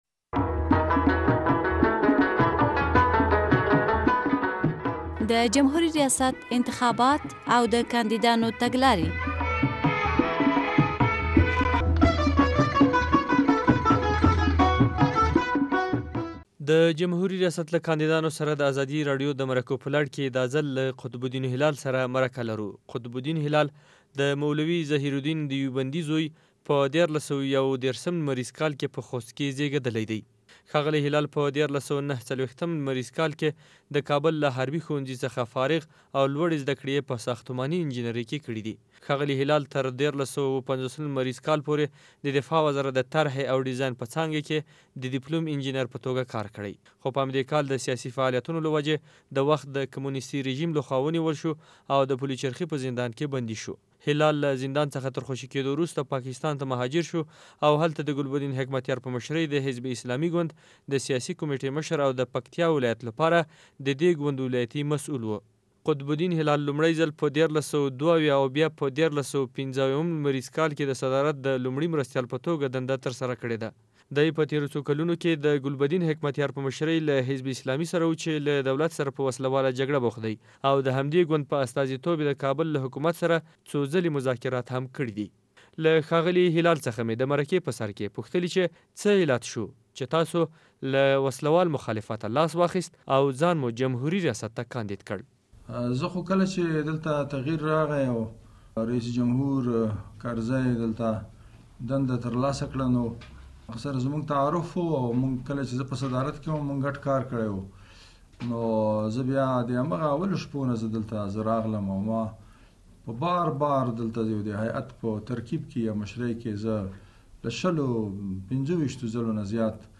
له قطب الدین هلال سره ځانګړې مرکه